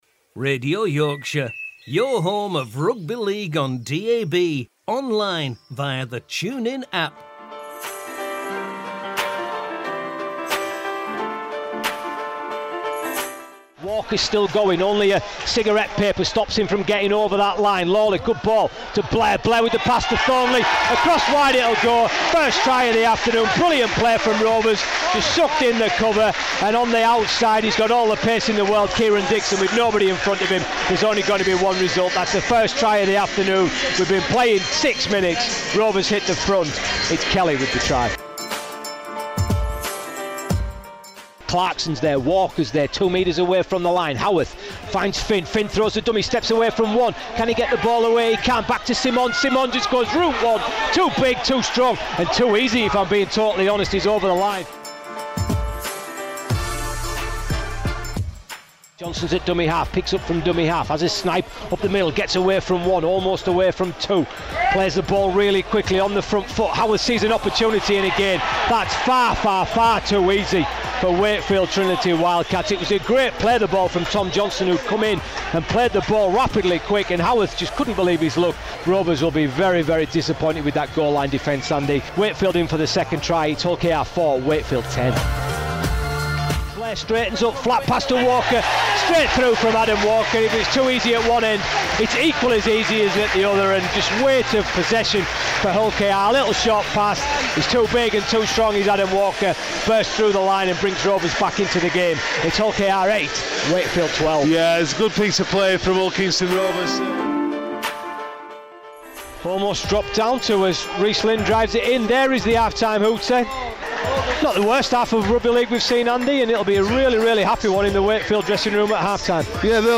RL on RY: Hull KR V WTW Highlights